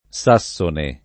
S#SSone; ant. o poet. SaSS1ne] etn. — sim. S. pers. m. stor.: es. Sassone il Grammatico [